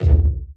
• 00's Strong Bottom End Hip-Hop Kick Drum Sample D Key 185.wav
Royality free bass drum single shot tuned to the D note. Loudest frequency: 100Hz
00s-strong-bottom-end-hip-hop-kick-drum-sample-d-key-185-AWj.wav